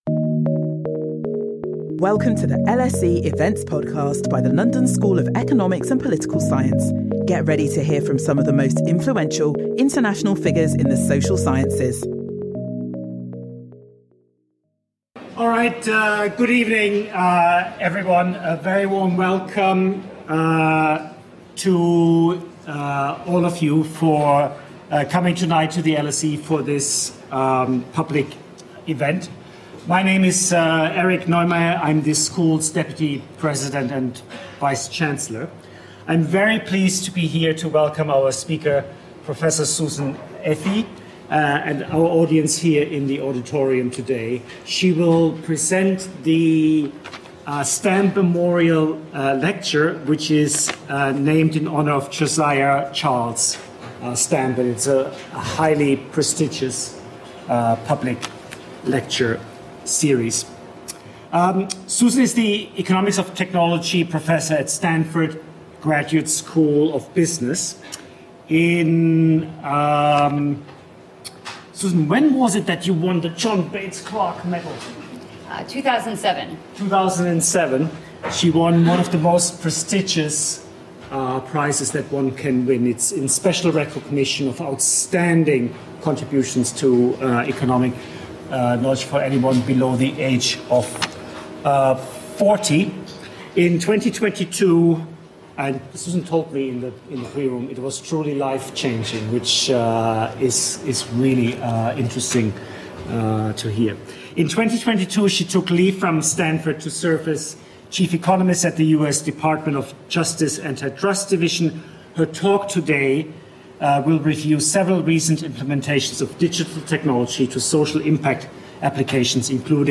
Join us for the Stamp Memorial Lecture which this year will be delivered by Susan Athey, the Economics of Technology Professor at Stanford Graduate School of Business.